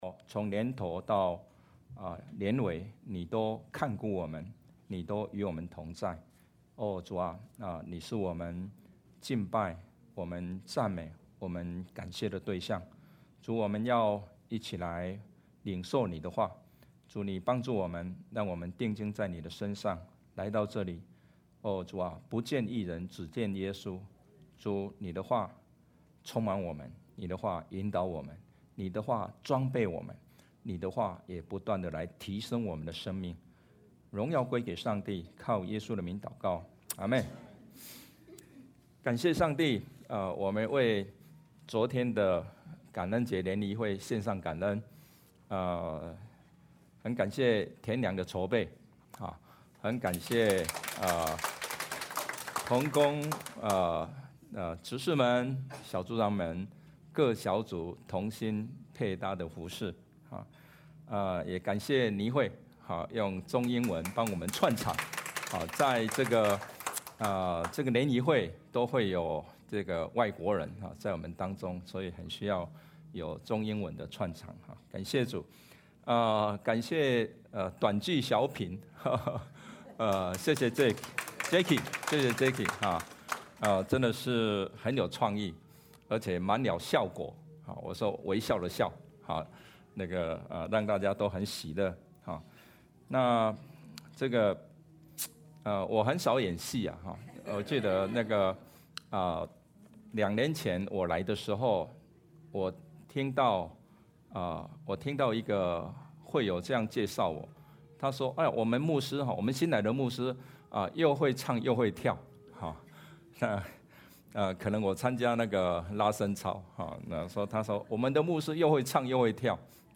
Judges 6:1–24 Service Type: 主日证道 Download Files Notes « 感恩 基甸的一生 » Submit a Comment Cancel reply Your email address will not be published.